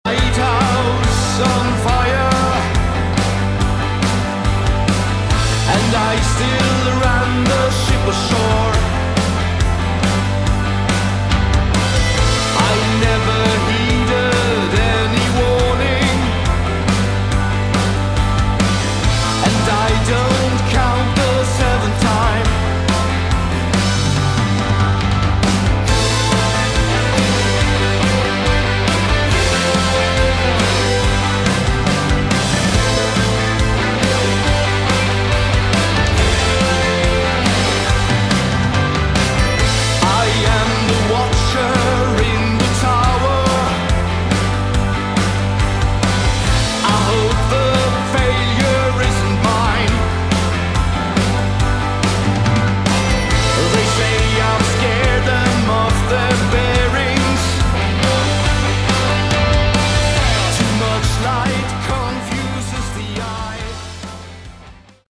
Category: Symphonic Hard Rock
synthesizers, vocals
rhythm, lead & acoustic guitars, bass, lead vocals